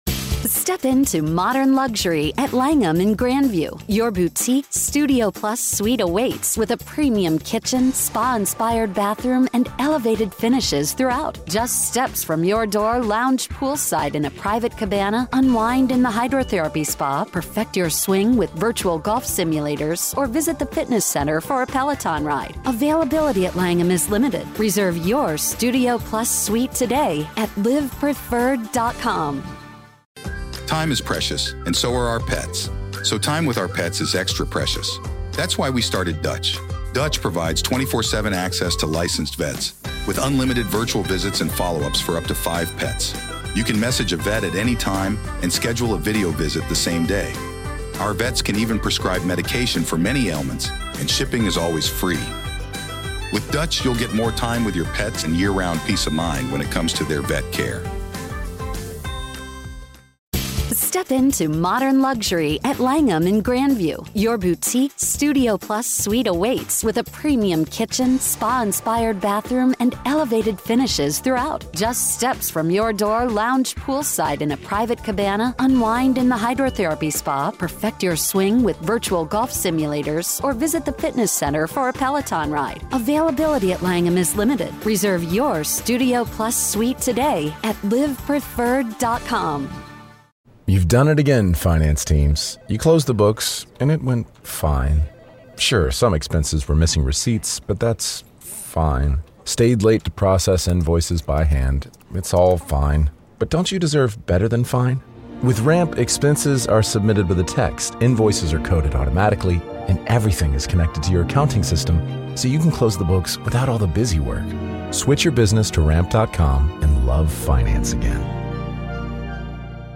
Welcome to a new episode of the Hidden Killers Podcast, where we bring you live courtroom coverage of some of the most gripping and heart-wrenching cases.